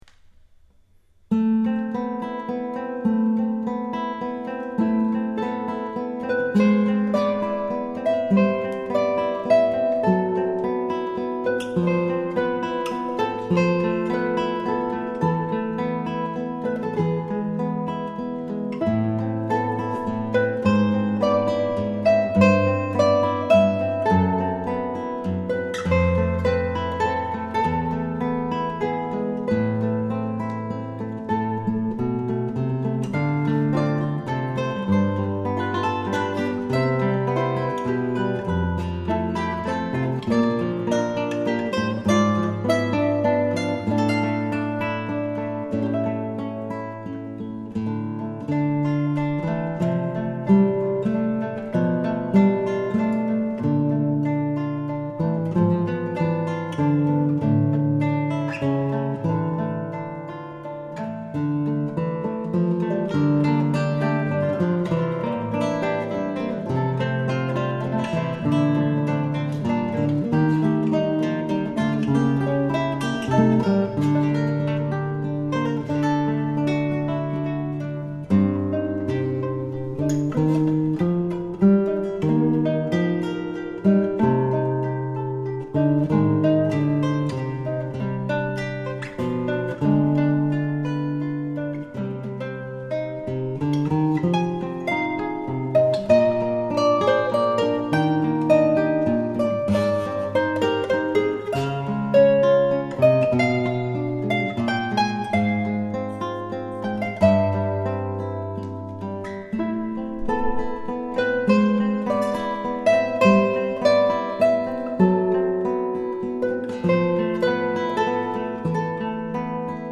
Ton vibrato rend l'expression encore plus prenante.